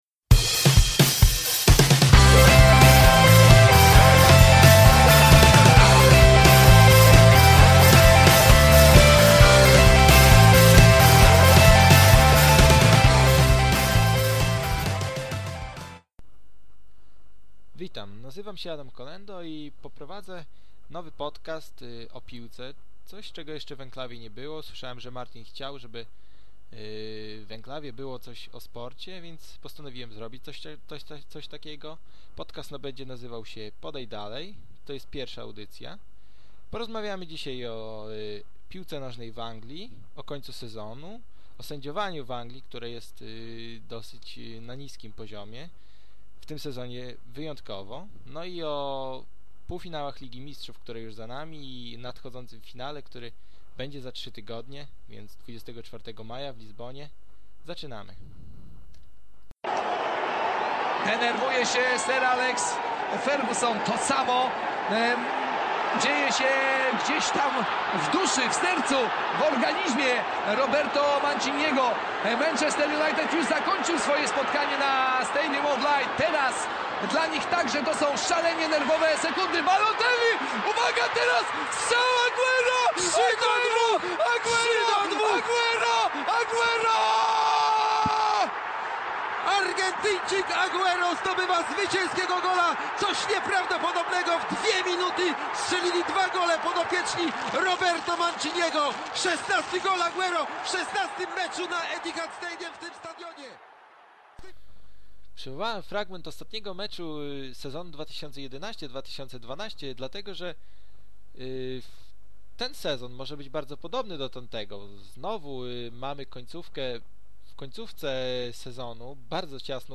Technicznie podcast bardzo dobry.